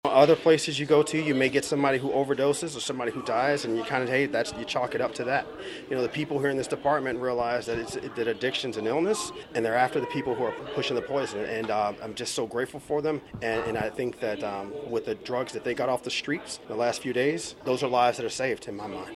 On Wednesday, leadership from RCPD, the Kansas Highway Patrol and Drug Enforcement Administration gathered at the Riley County Attorney’s Office building to announce four more arrests as part of their ongoing investigation.